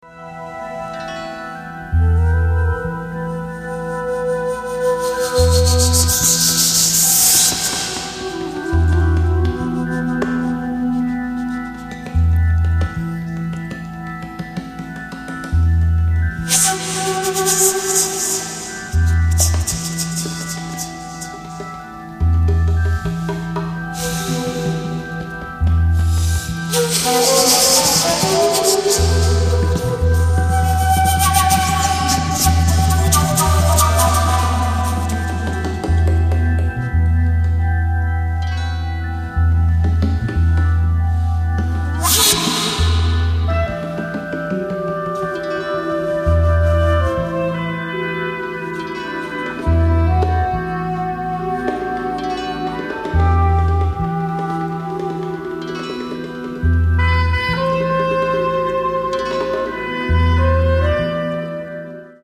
drums
trumpet/flugelhorn
elec. piano/Hammond Organ/piano
elec.guitar/fork guitar/sitar